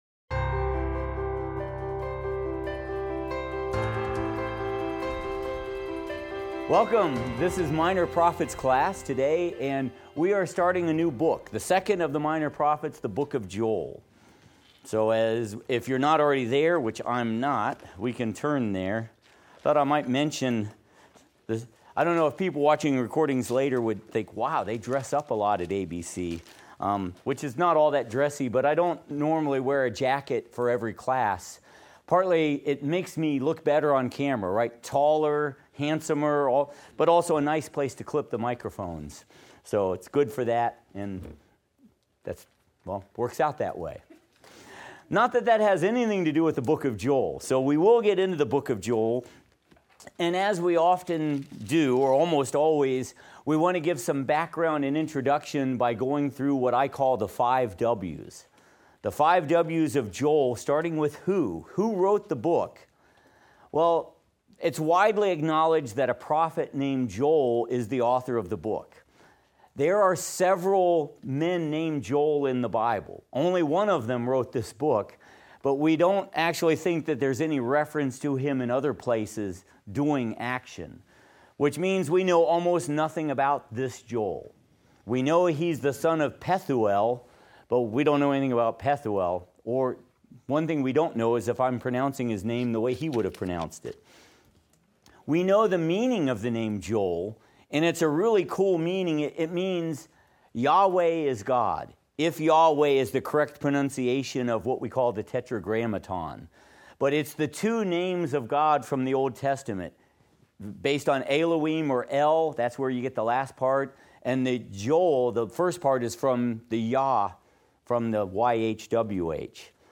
Minor Prophets - Lecture 6 - audio_0.mp3